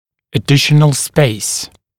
[ə’dɪʃənl speɪs][э’дишэнл спэйс]дополнительное место